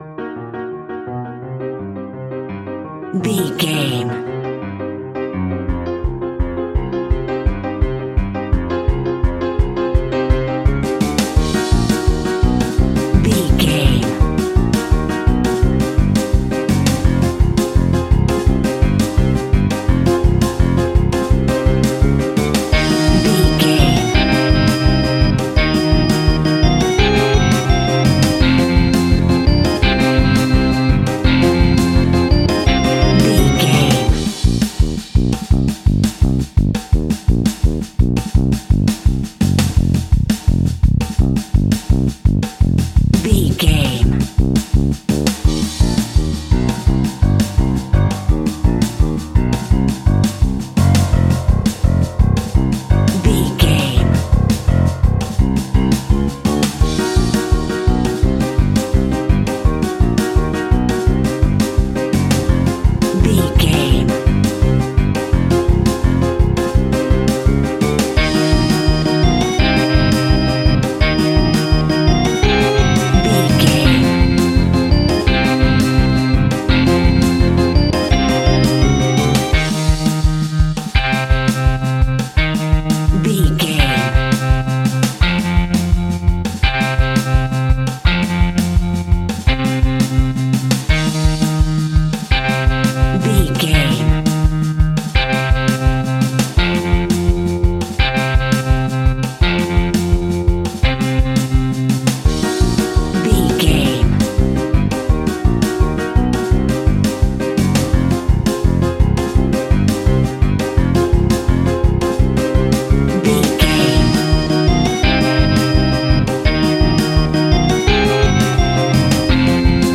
Atonal
ominous
eerie
instrumentals
horror music
Horror Pads
horror piano
Horror Synths